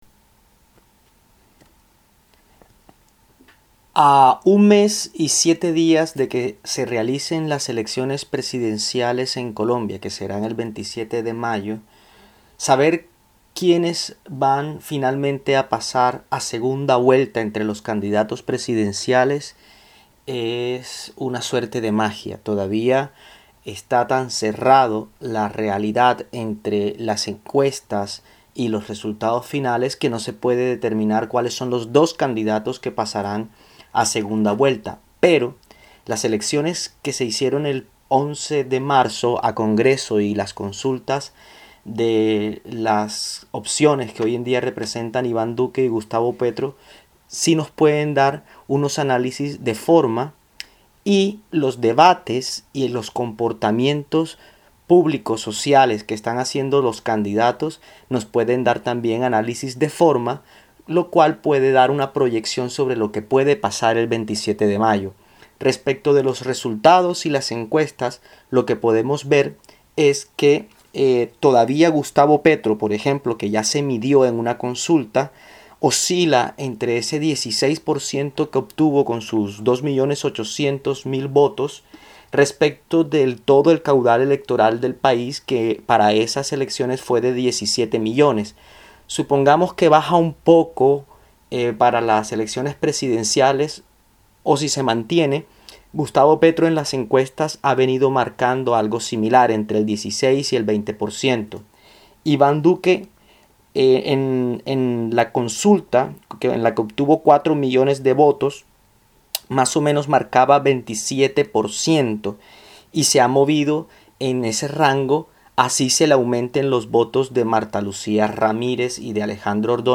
este resumen de voz: